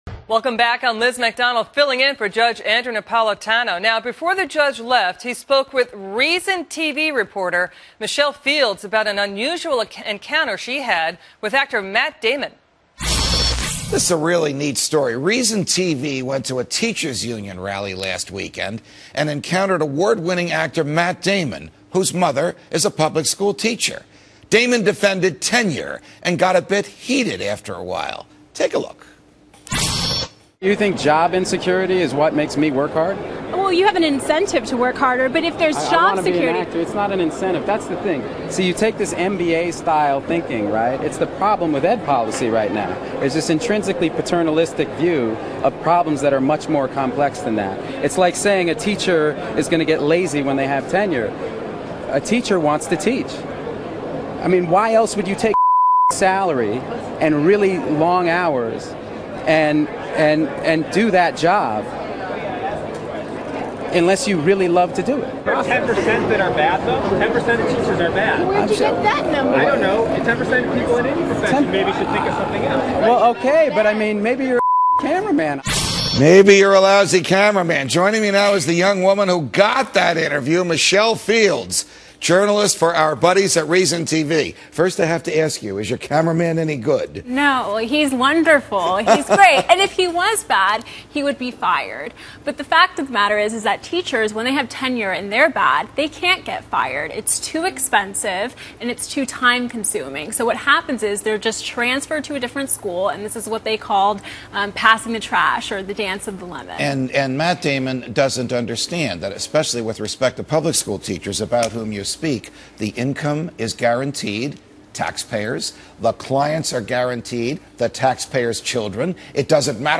Journalist Michelle Fields discusses her interview with Matt Damon and how teachers unions and government bureaucracy is harming education with Judge Napolitano. Air date: August 5, 2011.